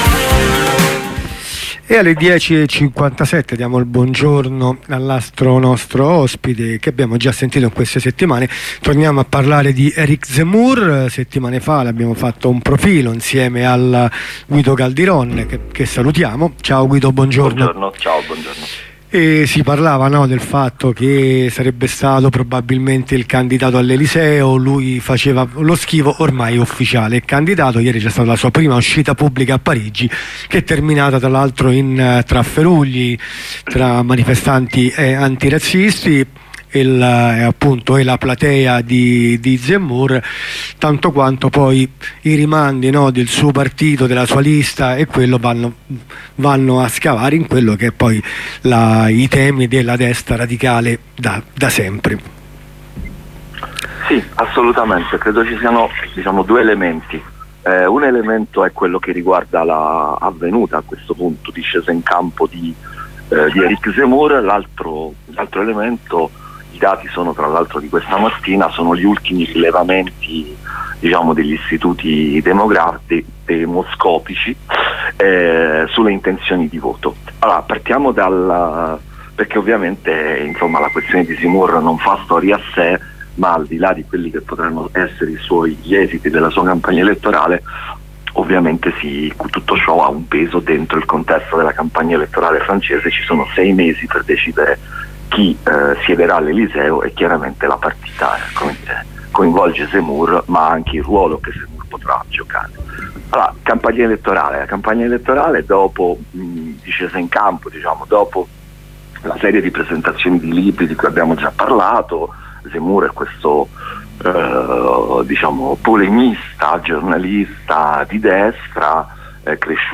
Redazionale a più voci